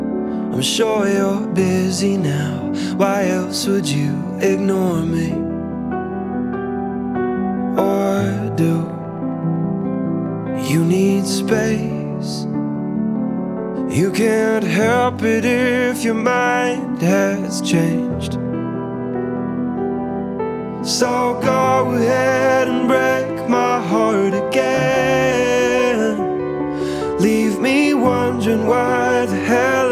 • Singer/Songwriter
An indie pop ballad